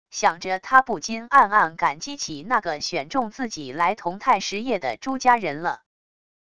想着他不禁暗暗感激起那个选中自己来同泰实业的朱家人了wav音频生成系统WAV Audio Player